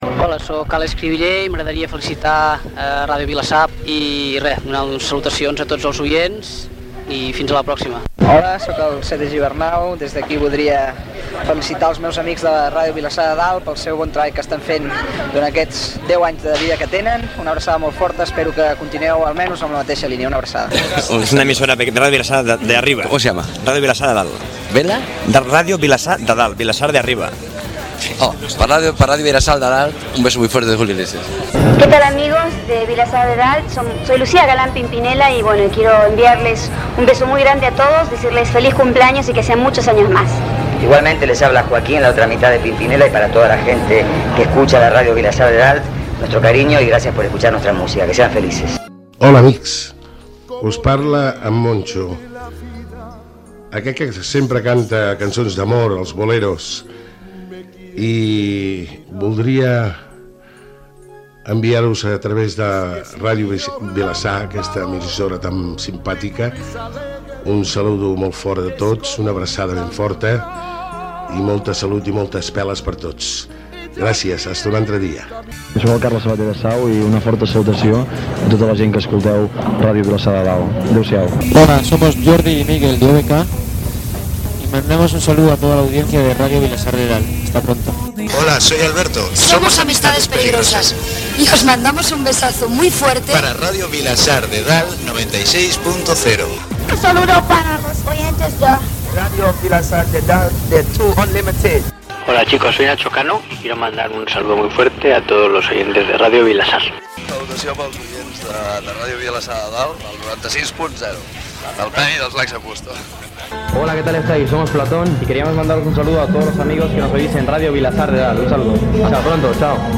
Felicitacions i salutacions amb motiu del 20è aniversari de Ràdio Vilassar de Dalt:Alex Crivillé, Sete Gibernau, Julio Iglesias, Pimpinela, Moncho, Carles Sabater (del grup Sau), OBK, Amistades Peligrosas, 2Unlimited, Nacho Cano, Lax n' Busto, Platón, La Frontera
FM